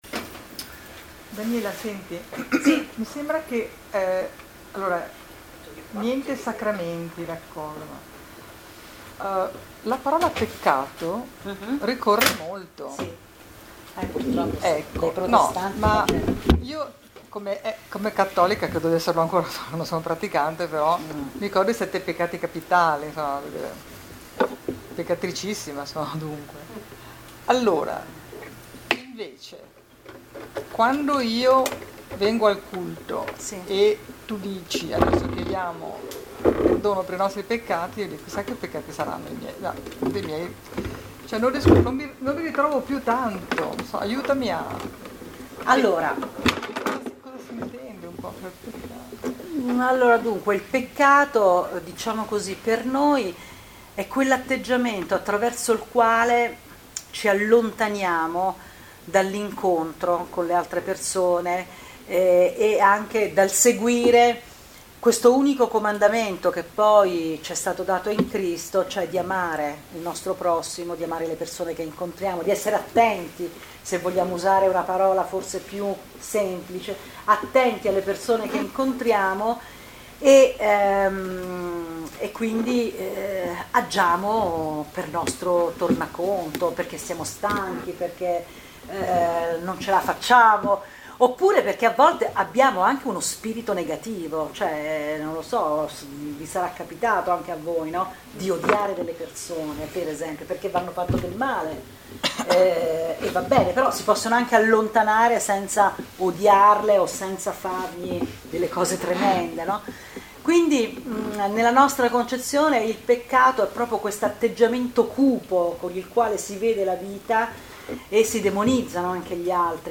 incontro nella chiesa Valdese di MIlano
Presentazione del tempio Valdese storia della chiesa Valdese i poveri di Lione domande e risposte Valli Valdesi